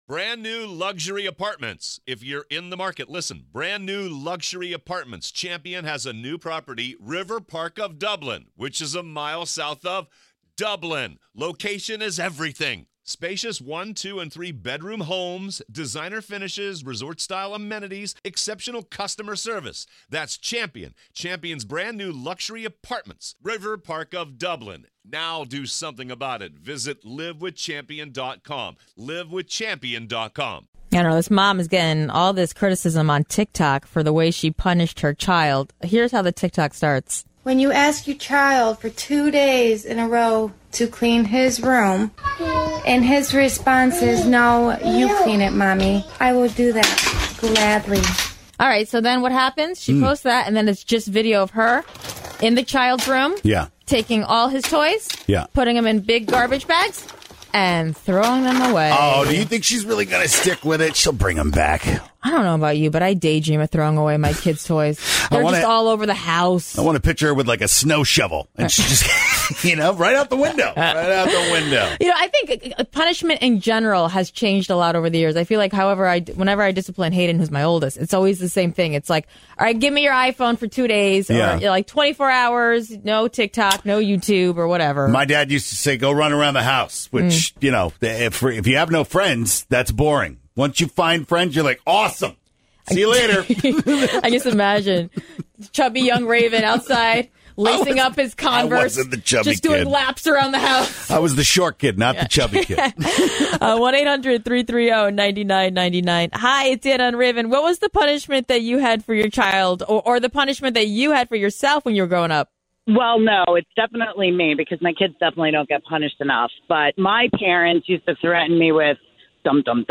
What’s the craziest punishment you ever gave your kids? How about the craziest punishment your parents gave you? A mom on Tiktok has gone viral for throwing out all her son’s toys after he refused to clean his room, but she seems tame compared to some of the people calling in!